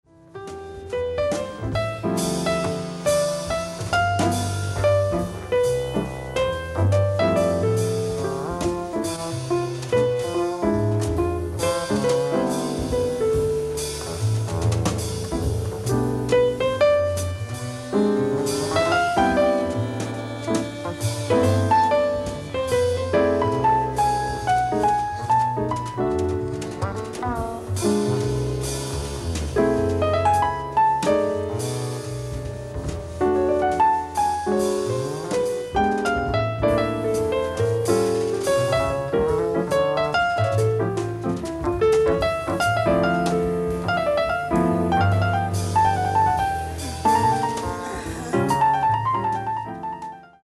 ライブ・アット・ルガーノ、スイス
※試聴用に実際より音質を落としています。